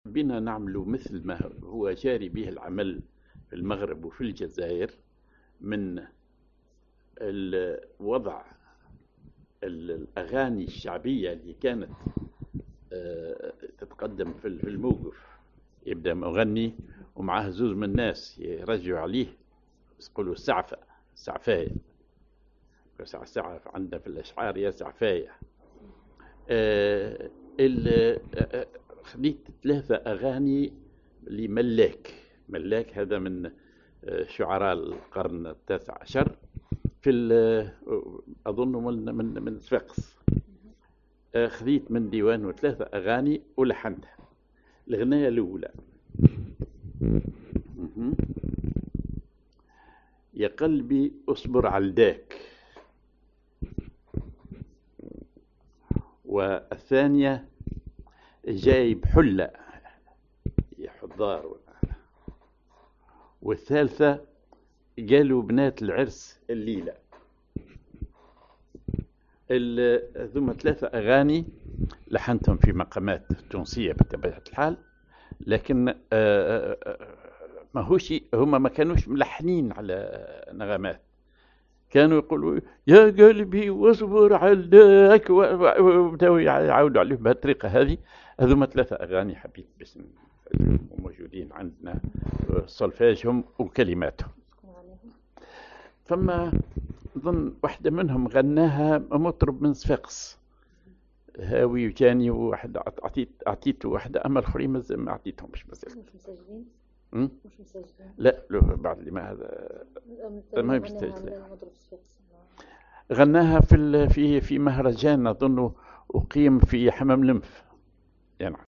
Maqam ar حسين
genre أغنية